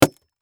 Knife_Generic2.wav